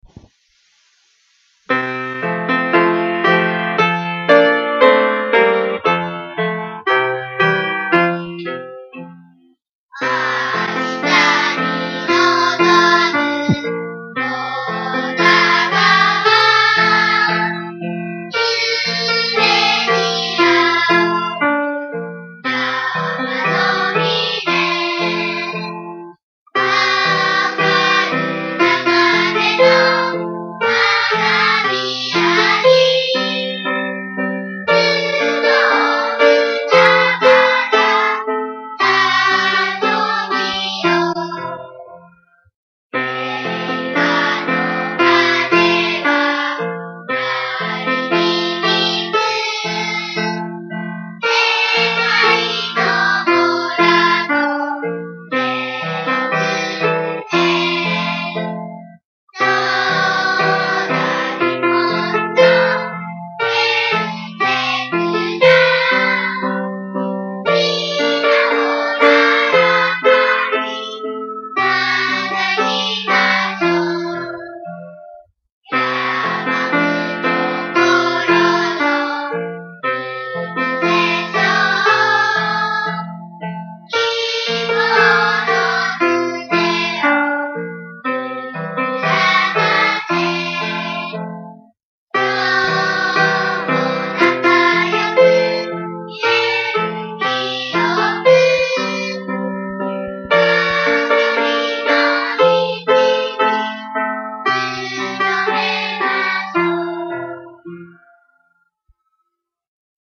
筒瀬小学校　校歌